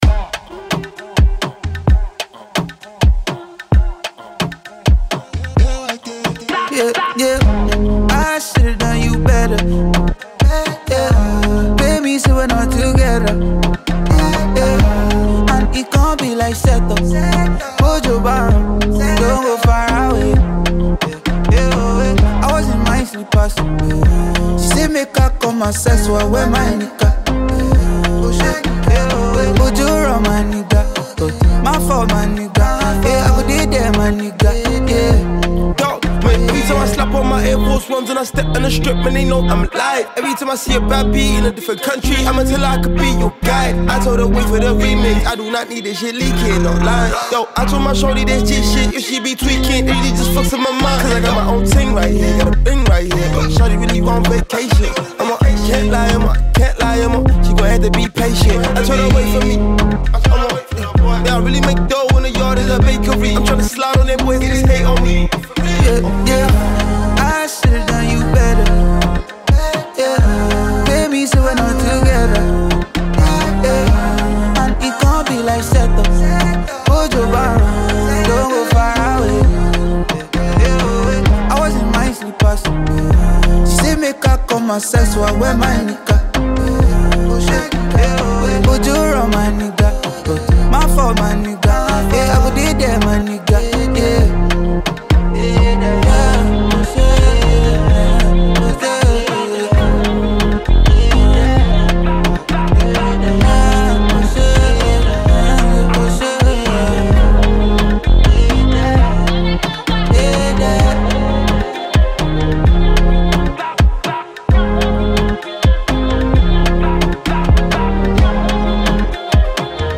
Nigerian singer-songwriter and alternative hip hop artist
renowned Nigerian vocalist